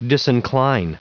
Prononciation du mot disincline en anglais (fichier audio)
Prononciation du mot : disincline